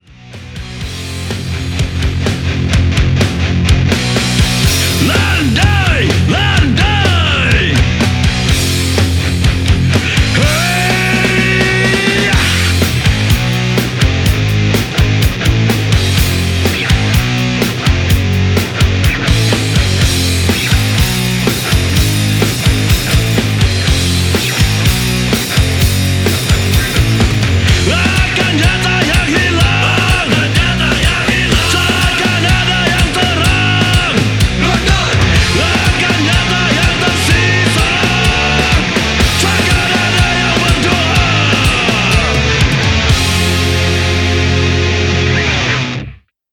Komplotan metallic hardcore/heavy rock asal Banda Aceh
Eksplorasi sound baru dan menyinggung omong kosong
vokal
gitar
bass
drum